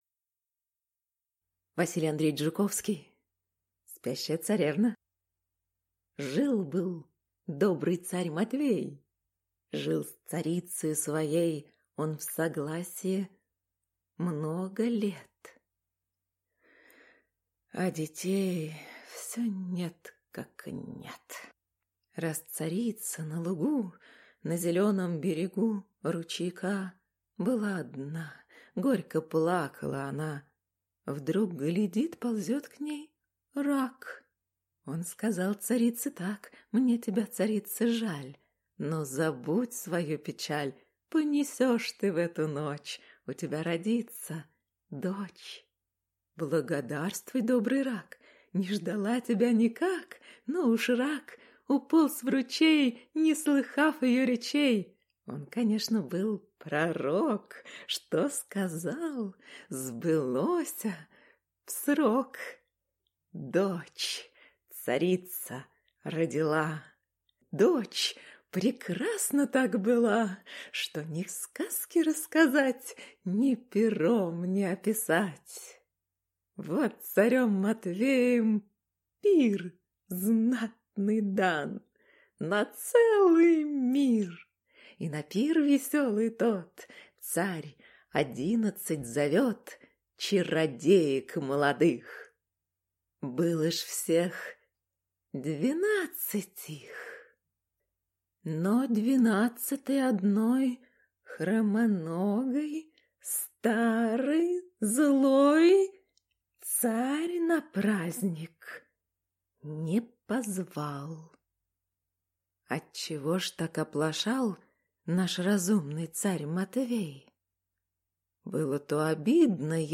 Аудиокнига Спящая царевна | Библиотека аудиокниг